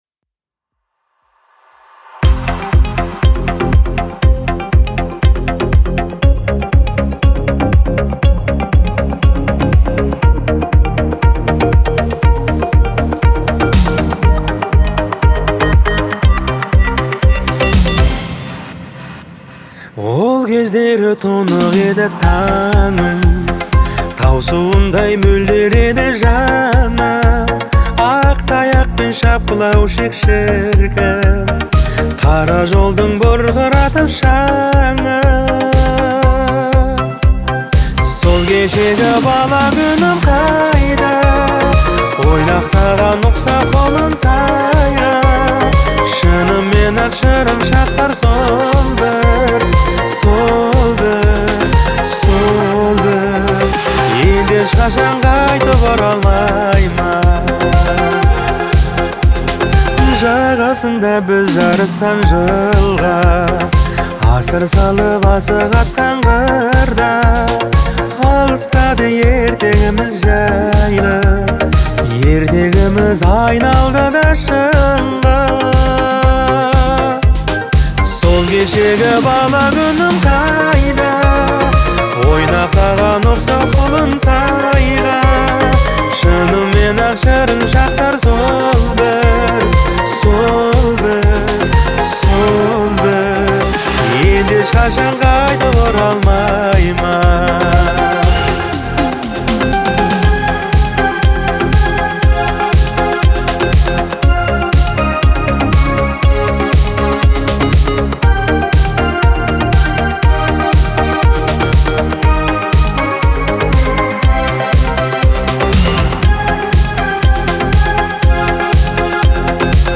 это трогательное произведение в жанре поп-музыки